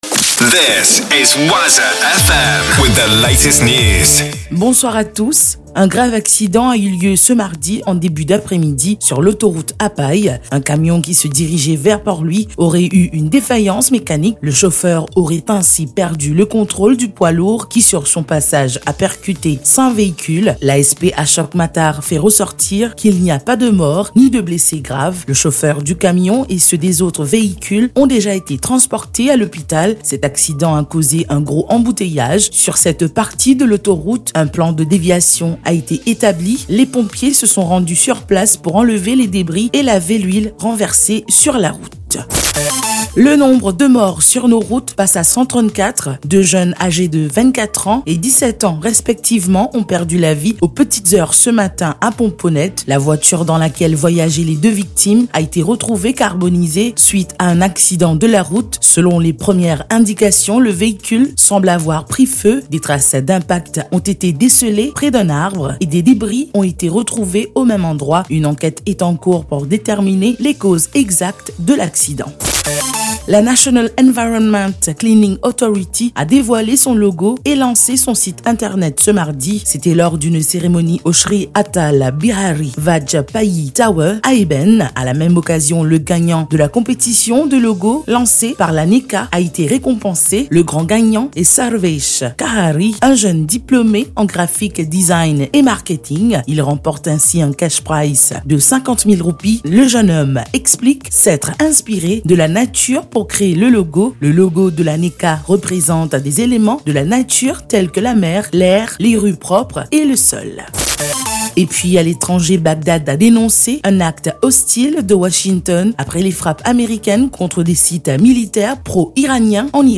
NEWS 19H - 26.12.23